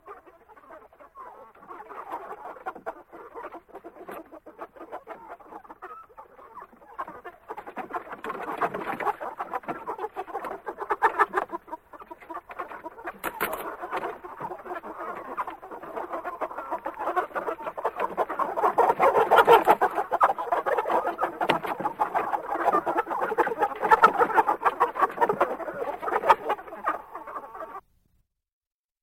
chicken-coop.mp3